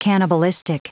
Prononciation du mot cannibalistic en anglais (fichier audio)
Prononciation du mot : cannibalistic
cannibalistic.wav